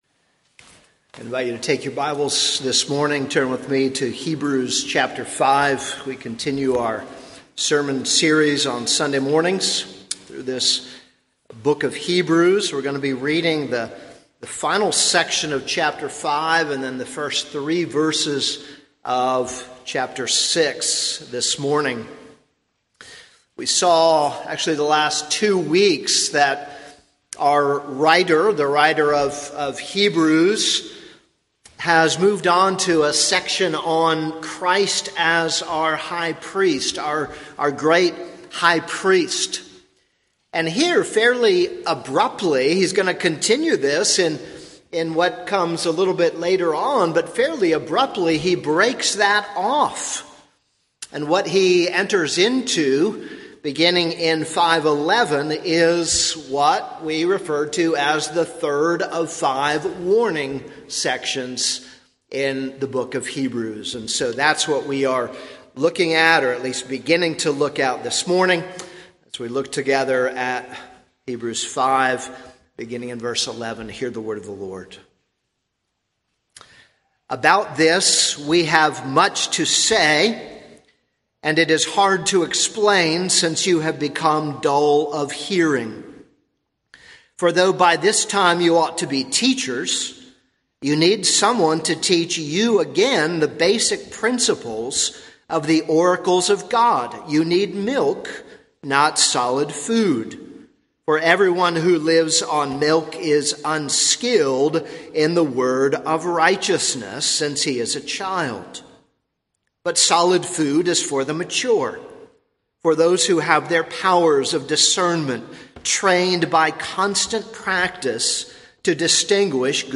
This is a sermon on Hebrews 5:11-6:3.